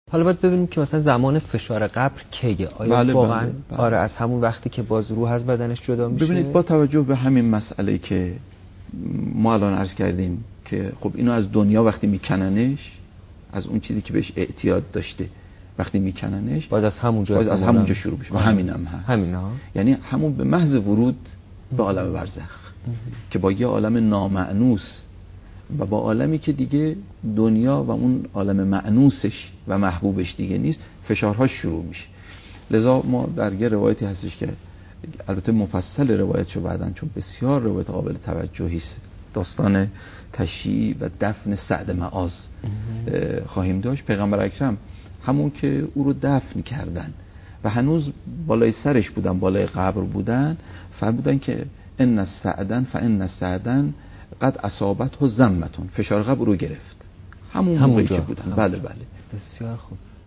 آخرین خبر/ سخنرانی شنیدنی درباره زمان فشار قبر را بشنوید.